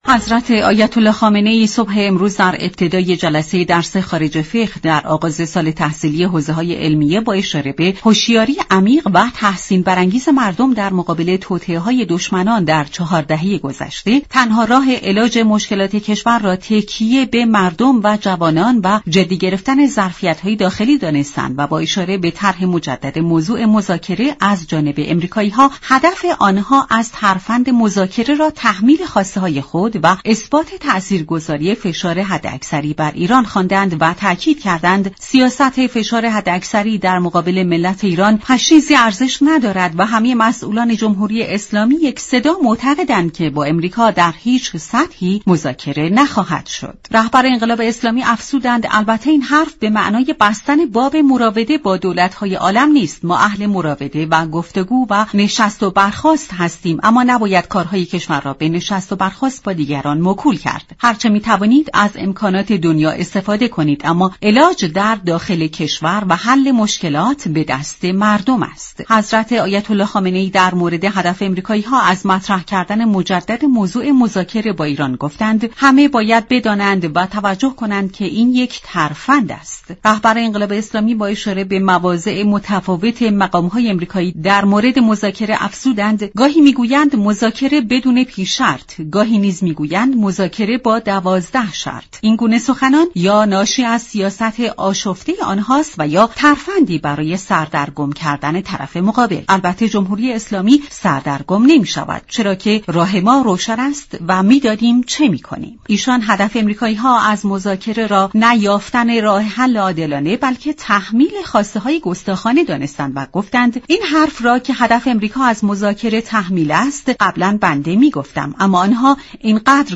وی ادامه داد: دولتی كه علاوه بر زیر پا گذاشتن توافقنامه برجام، تمامی تعهدات بین المللی خود از جمله معاهدات آب و هوایی پاریس، توافق موشكی با روسیه ،پیمان تجاری اقیانوس آرام و ... را عملیاتی نمی كند ، هیچگونه پشتوانه ای برای تعهدات جدید ندارد. برنامه جهان سیاست شنبه تا چهارشنبه هر هفته ساعت 15:30 از رادیو ایران پخش می شود.